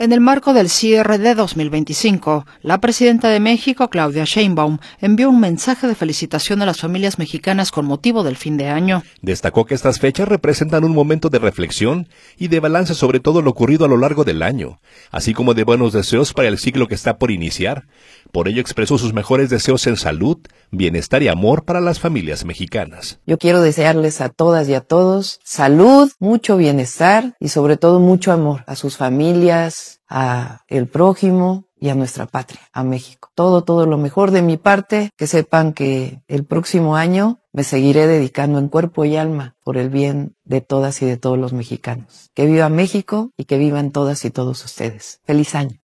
Sheinbaum envía mensaje de fin de año a las familias mexicanas
En el marco del cierre de 2025, la presidenta de México, Claudia Sheinbaum, envió un mensaje de felicitación a las familias mexicanas con motivo del fin de año. Destacó que estas fechas representan un momento de reflexión y de balance sobre lo ocurrido a lo largo del año, así como de buenos deseos para el ciclo que está por iniciar, por ello expresó sus mejores deseos de salud, bienestar y amor para las familias mexicanas.